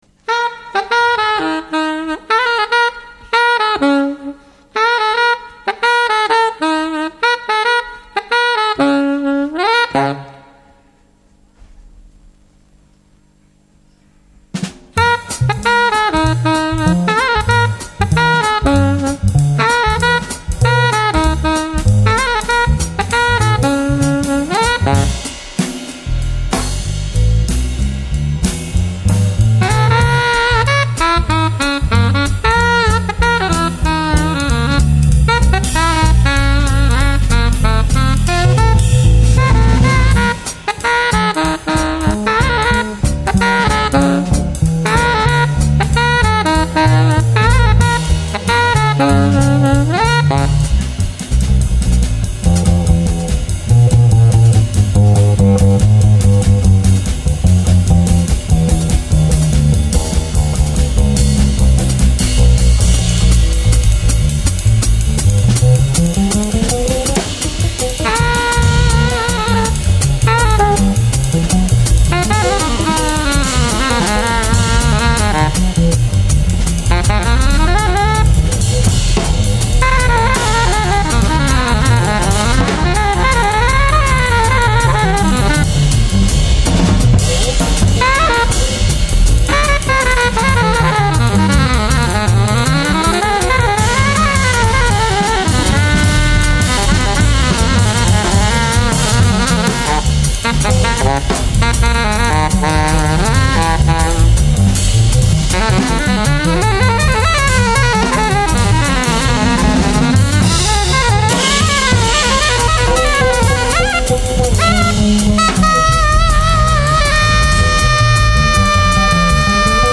Disc 2 recorded in concert at Jazz Club Fashing,
Stockholm, Sweden on September 12, 1985.